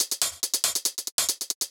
UHH_ElectroHatA_140-05.wav